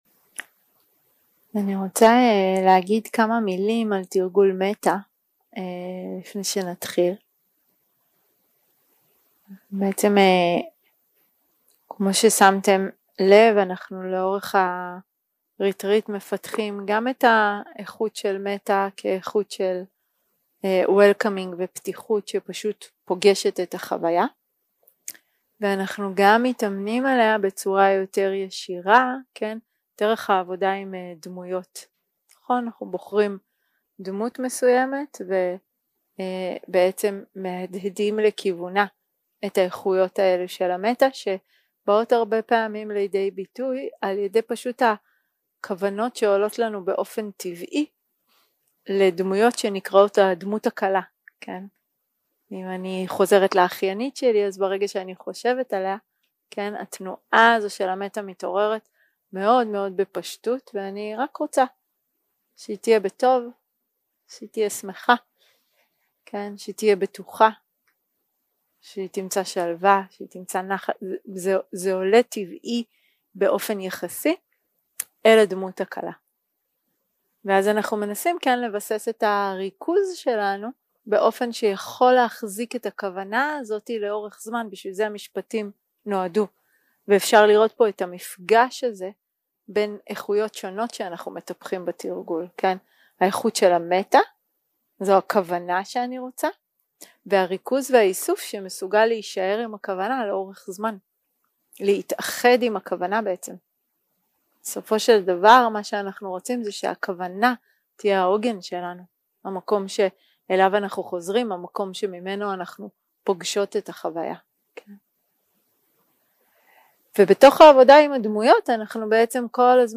יום 4 – הקלטה 9 – צהריים – מדיטציה מונחית – מטא לדמות הקלה, לעצמי ולכל
יום 4 – הקלטה 9 – צהריים – מדיטציה מונחית – מטא לדמות הקלה, לעצמי ולכל 0:00 0:00 סוג ההקלטה: Dharma type: Guided meditation שפת ההקלטה: Dharma talk language: Hebrew